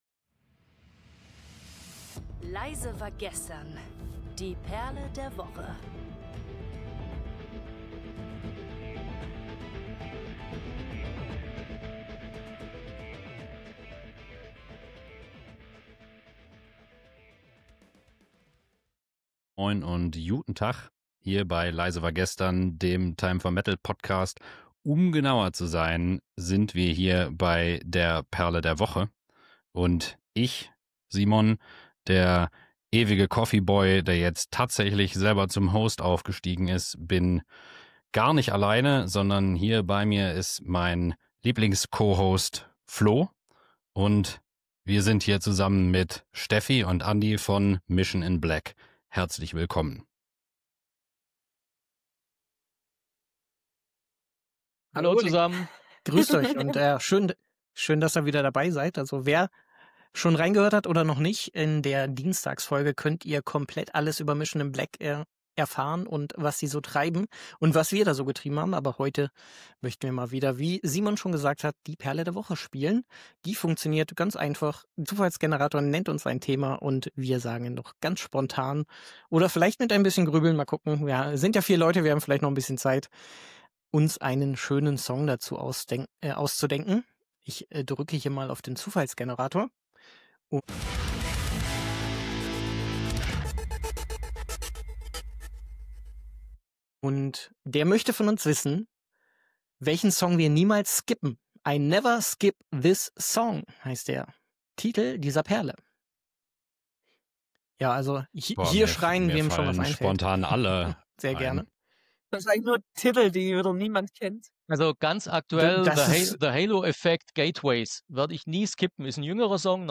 Ob Klassiker, moderne Hymnen oder Songs, die mit ganz persönlichen Erinnerungen verbunden sind – hier geht’s um die Stücke, die immer wieder den Weg in die Playlist finden. Dabei wird nicht nur gefachsimpelt, sondern auch herzlich gelacht, erzählt und diskutiert.
🎶 05:06 – Outro: Gojira – Backbone